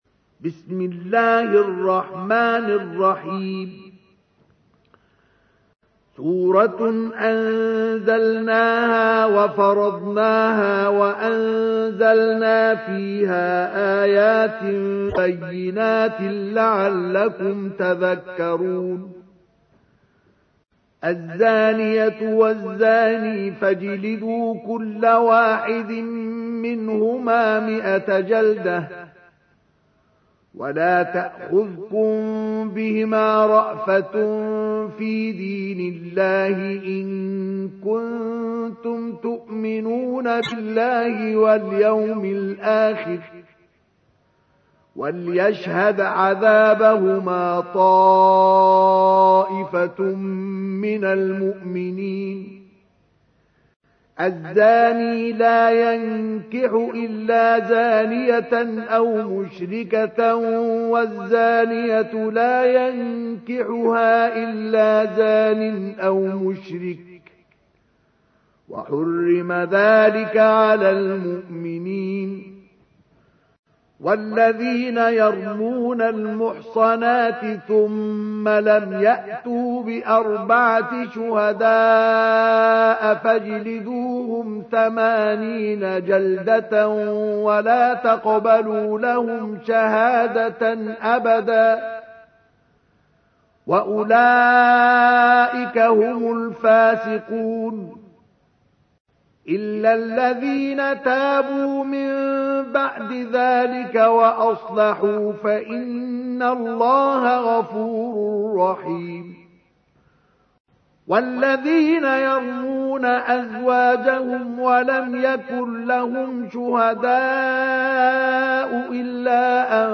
تحميل : 24. سورة النور / القارئ مصطفى اسماعيل / القرآن الكريم / موقع يا حسين